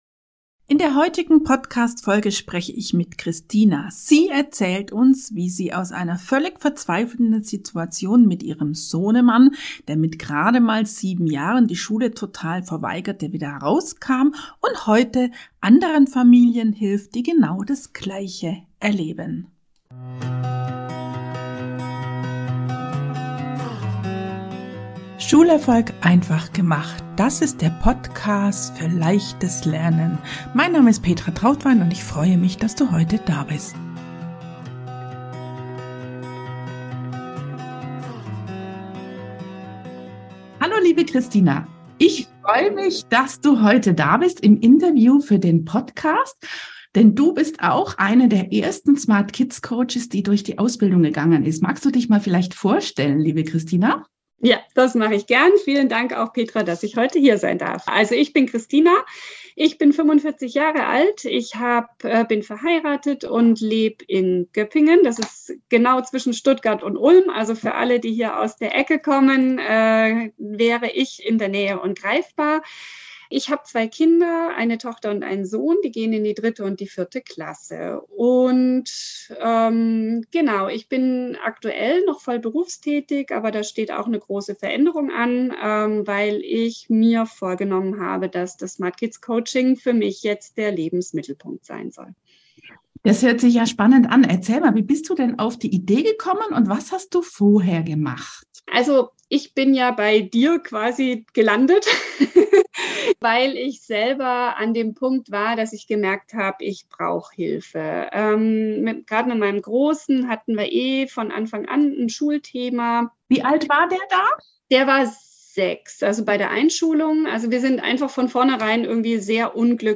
Interview aus der Coachingpraxis: Null Bock auf Hausaufgaben ~ Schulerfolg.